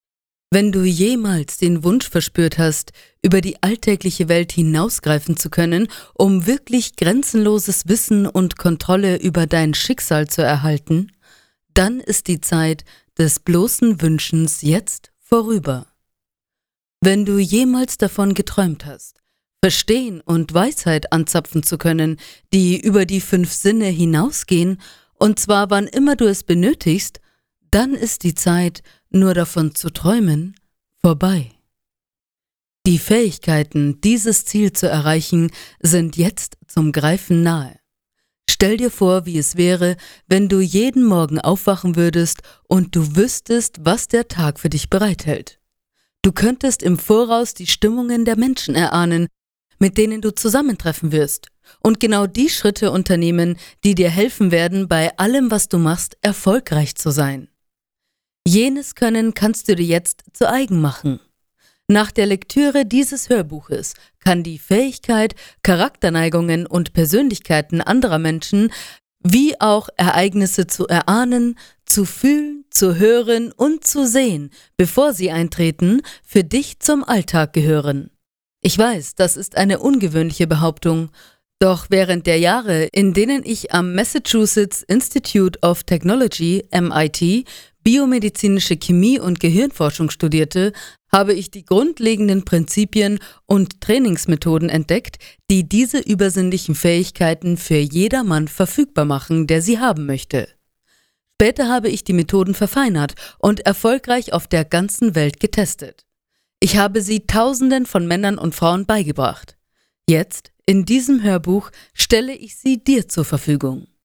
Ungekürztes Hörbuch